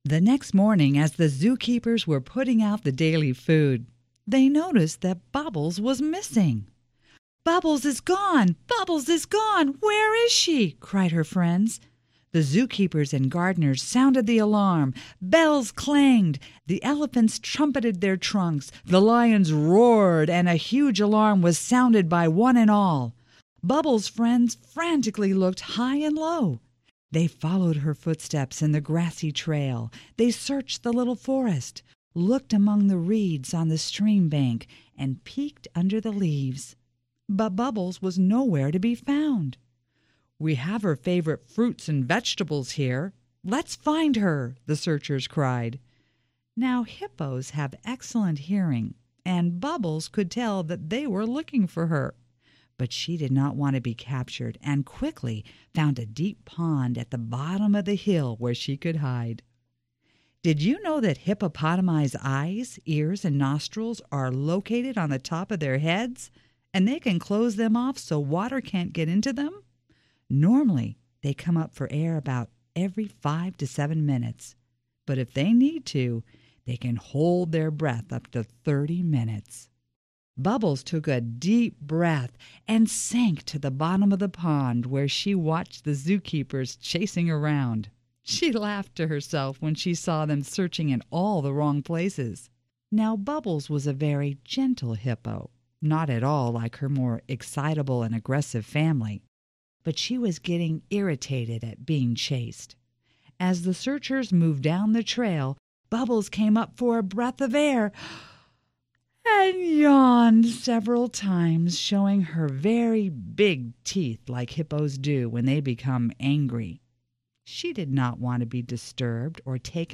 (Studio Recording, 1978)